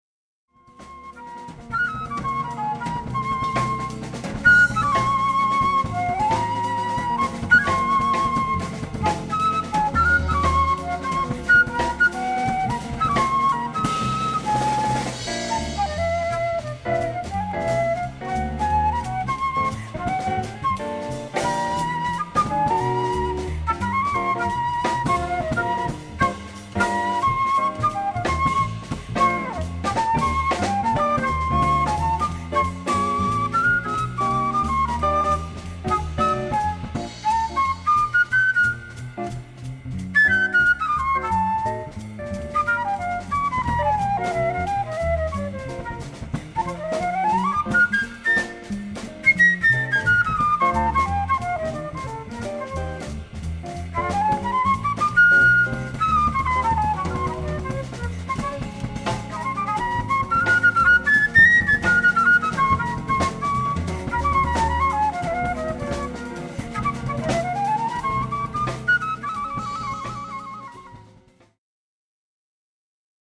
guitares
contrebasse
batterie